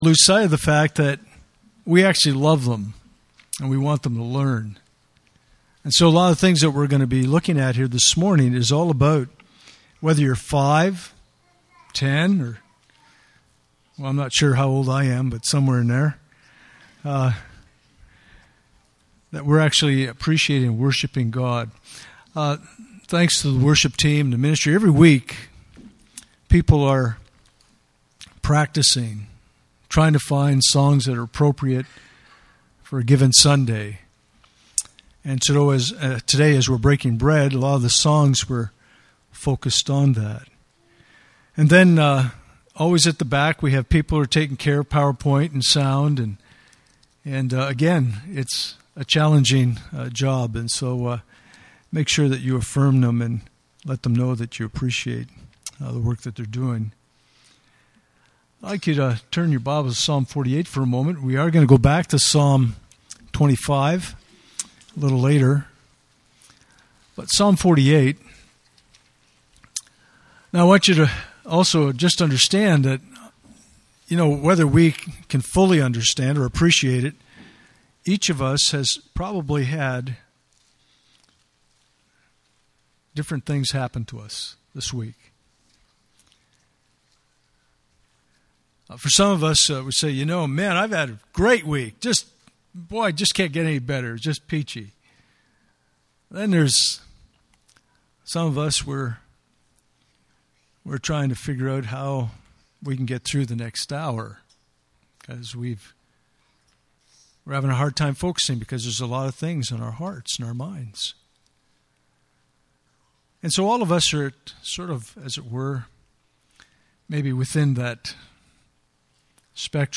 Communion Service
Communion Passage: Psalm 25:1-10 Service Type: Sunday Morning « Stewardship